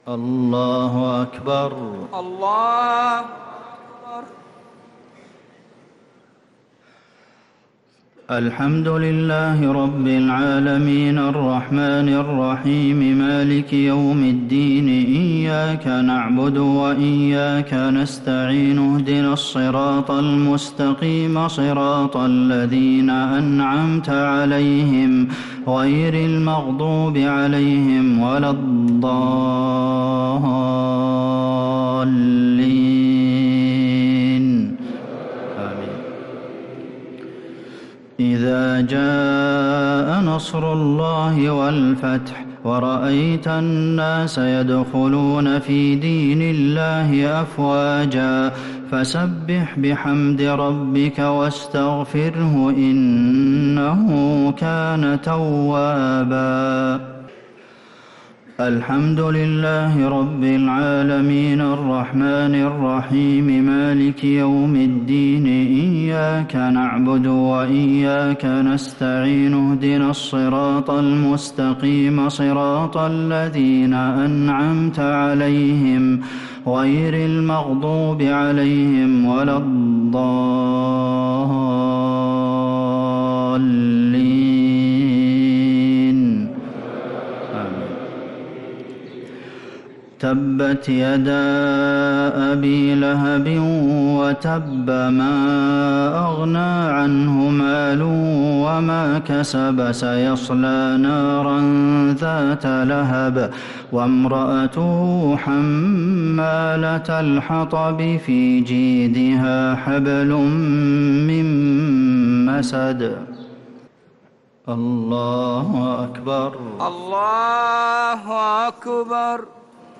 صلاة الشفع و الوتر ليلة 8 رمضان 1446هـ | Witr 8th night Ramadan 1446H > تراويح الحرم النبوي عام 1446 🕌 > التراويح - تلاوات الحرمين